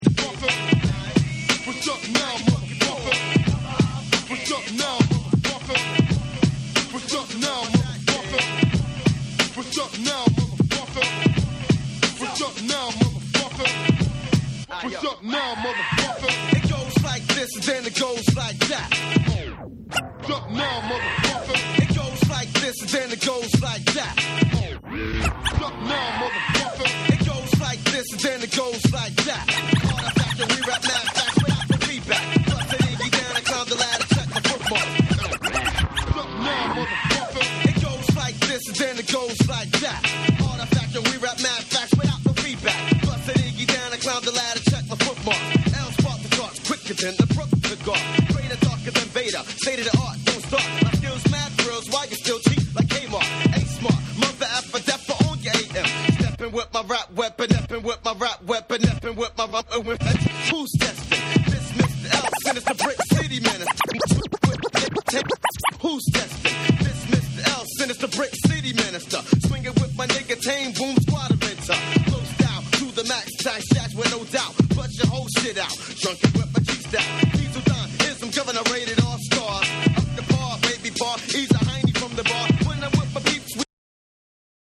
MIX CD / JAPANESE / HIP HOP